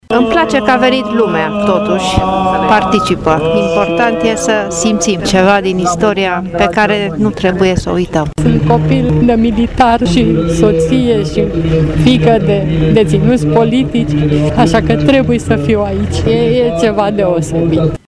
În ciuda frigului pătrunzător, cu mic, cu mare, brașovenii au luat parte la tradiționala manifestare dedicată zilei de 24 ianuarie, care s-a desfășurat într-un loc la fel de tradițional, Piața Unirii: